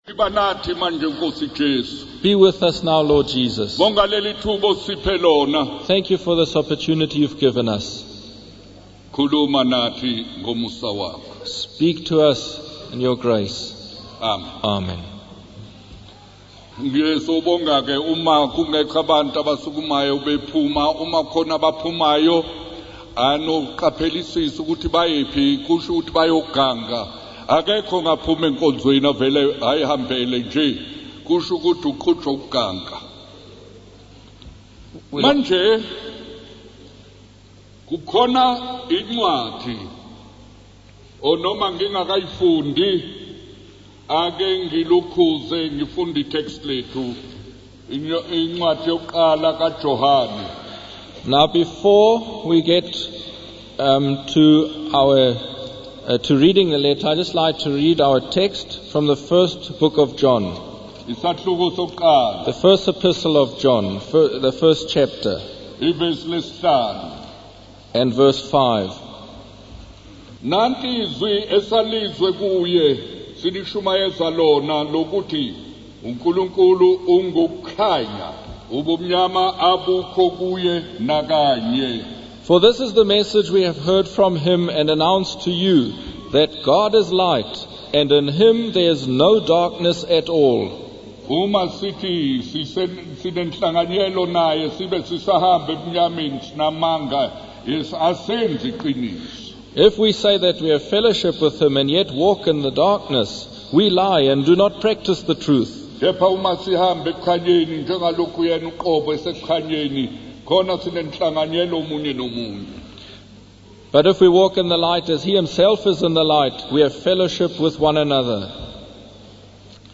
In this sermon, the preacher emphasizes the importance of examining one's own life and the impact they have on others. He urges listeners to consider whether they are living according to the Bible and if they are shining the light of God's holiness in the world.